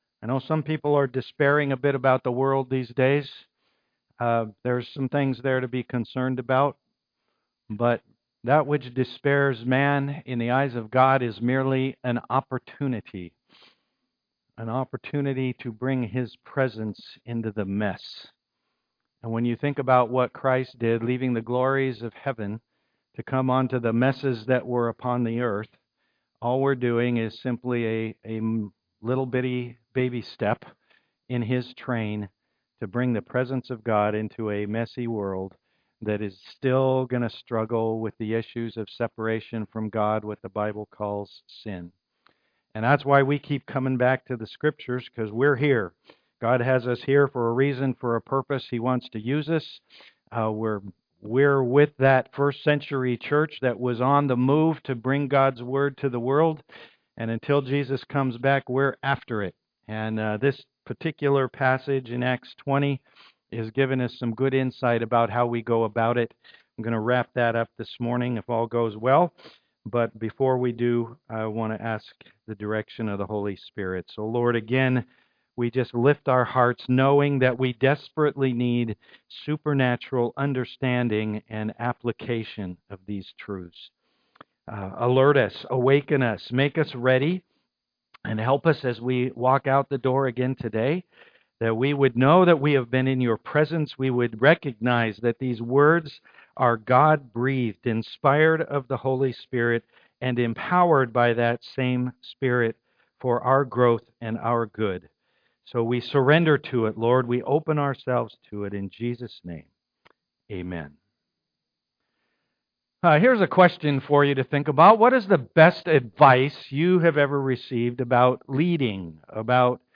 Acts 20:30-38 Service Type: am worship Leadership is never easy